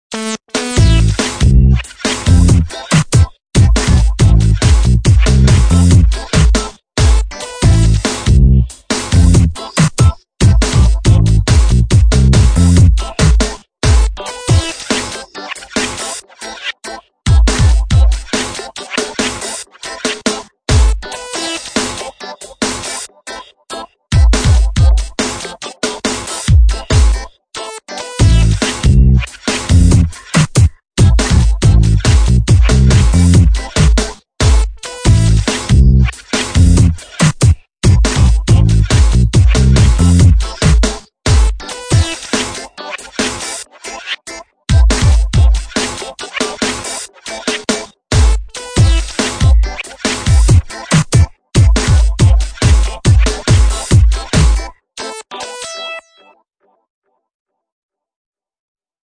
Leuk, maar niet 'warm' genoeg